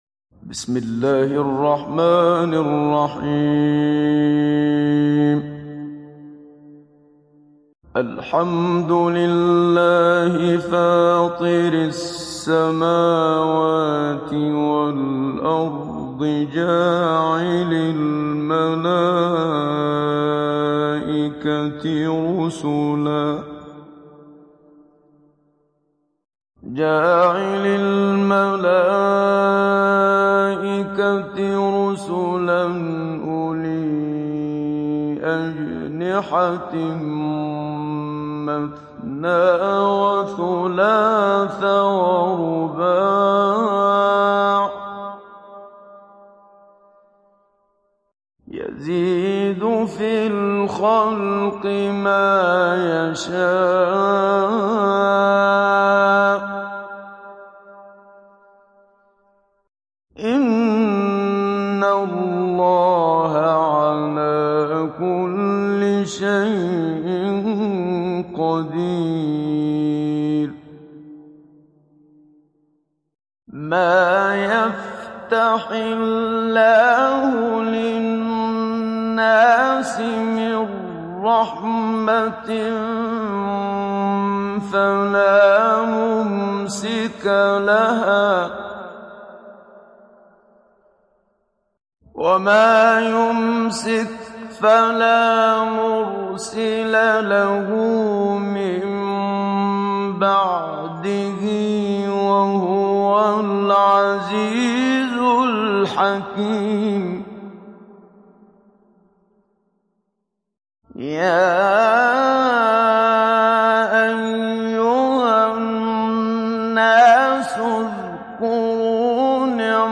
Télécharger Sourate Fatir Muhammad Siddiq Minshawi Mujawwad